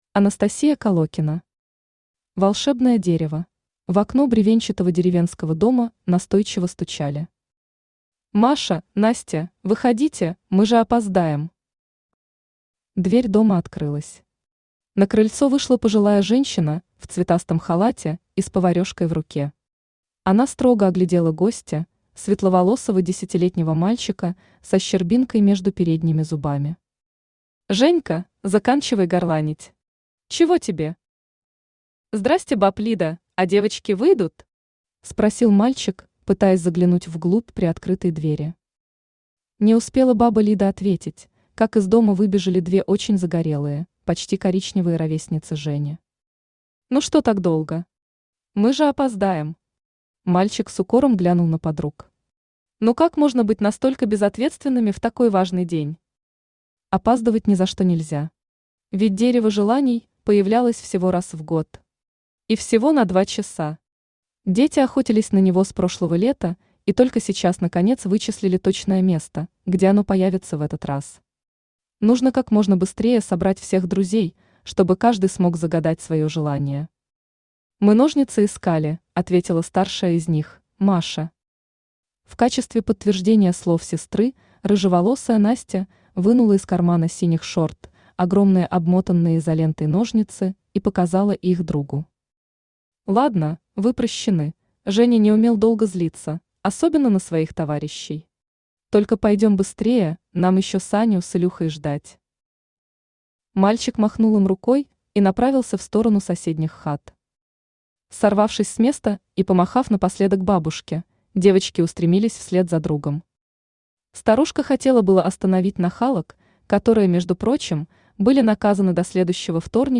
Аудиокнига Волшебное дерево | Библиотека аудиокниг
Aудиокнига Волшебное дерево Автор Анастасия Колокина Читает аудиокнигу Авточтец ЛитРес.